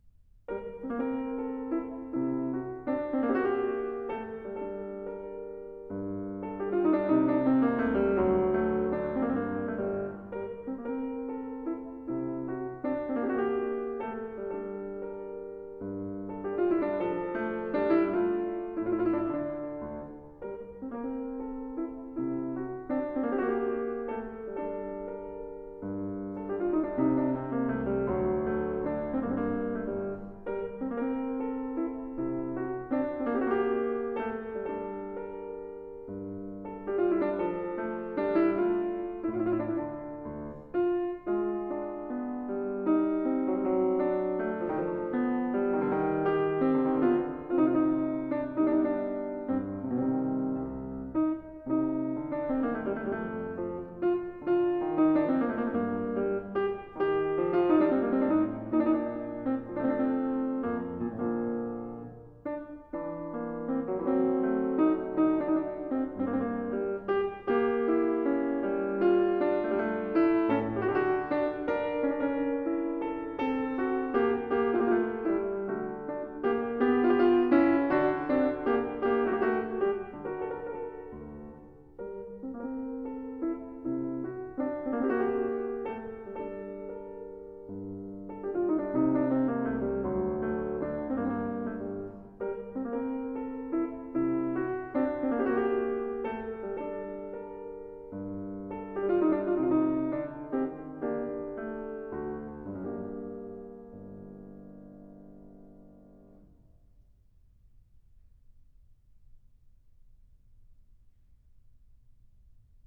interprétée au piano